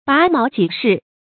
拔毛濟世 注音： ㄅㄚˊ ㄇㄠˊ ㄐㄧˋ ㄕㄧˋ 讀音讀法： 意思解釋： 指受輕微損失而能有利于世人。